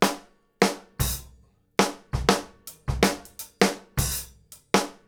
GROOVE 8 07R.wav